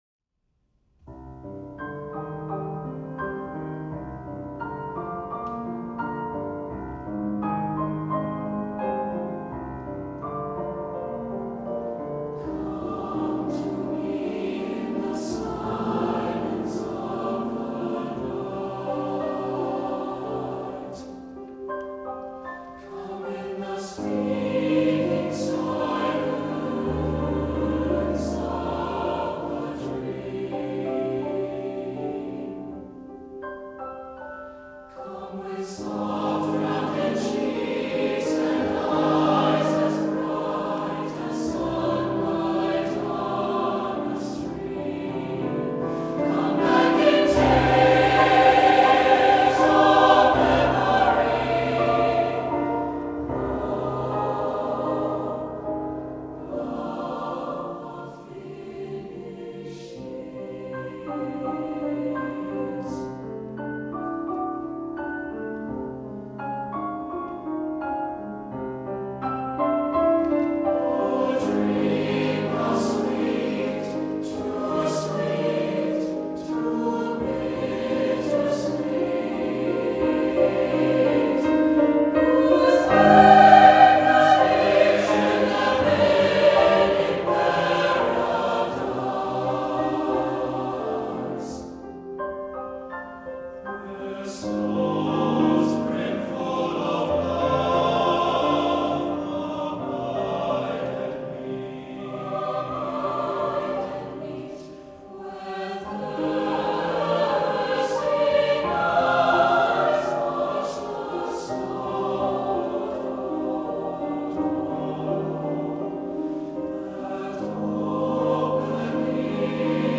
Voicing: SATB divisi